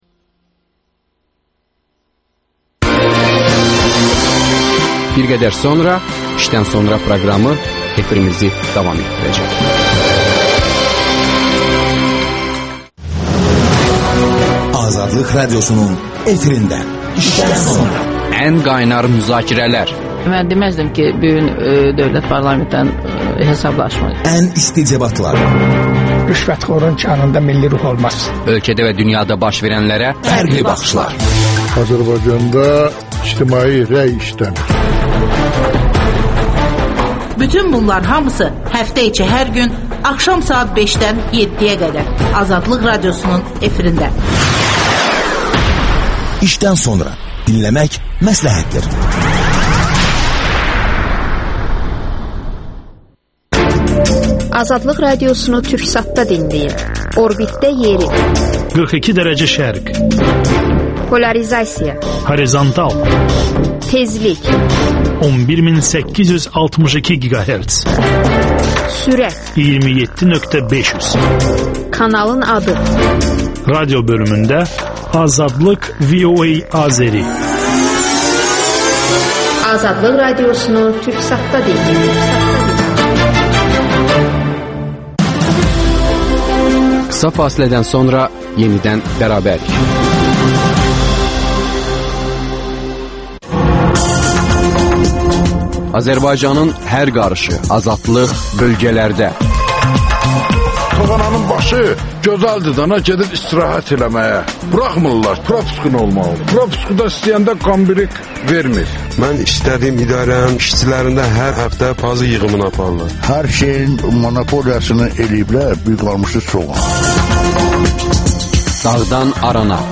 Quba rayonundakı etiraz aksiyaları barədə hadisə yerinə ezam olunmuş müxbirimiz, etiraz aksiyasında iştirak edən sakinlər məlumat verirlər.